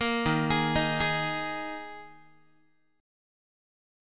Ebsus4 chord